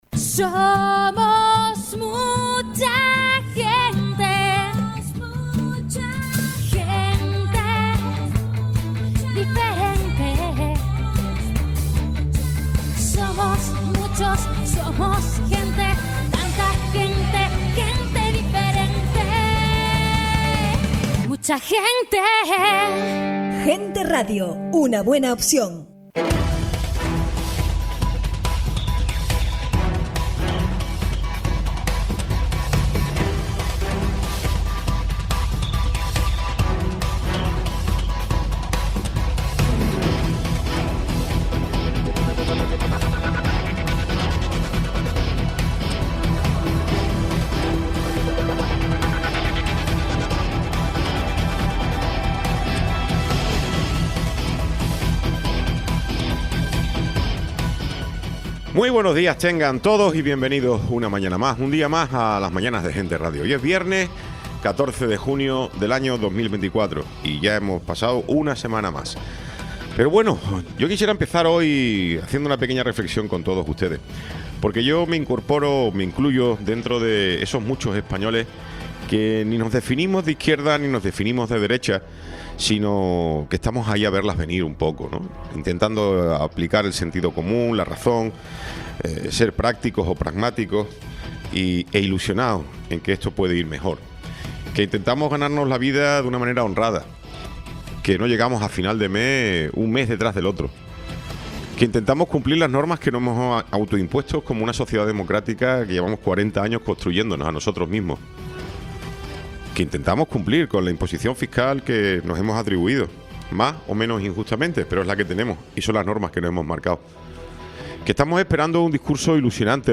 Tertulia
Programa sin cortes